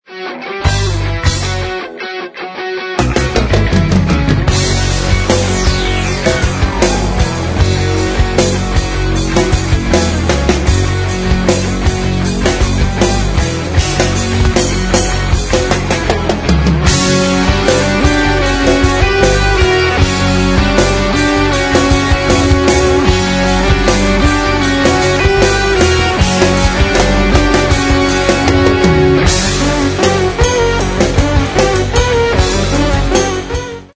A Progressive Rock Site